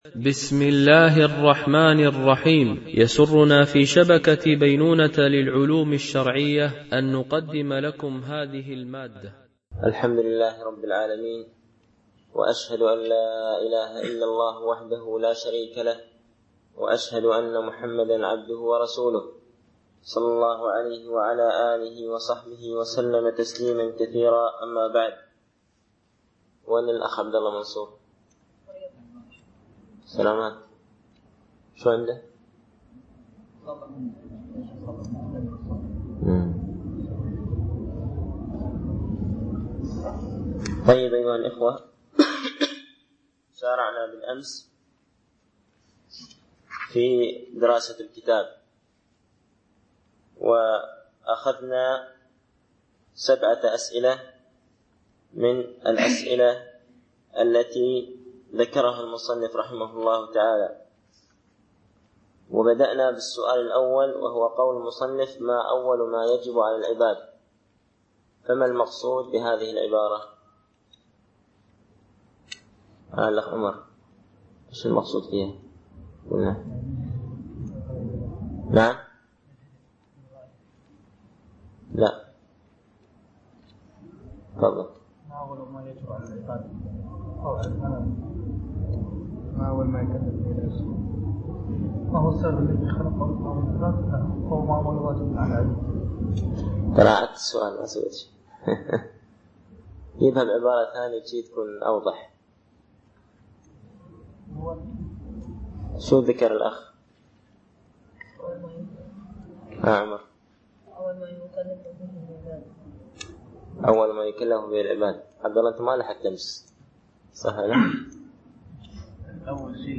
) الألبوم: شبكة بينونة للعلوم الشرعية التتبع: 3 المدة: 48:54 دقائق (11.23 م.بايت) التنسيق: MP3 Mono 22kHz 32Kbps (CBR)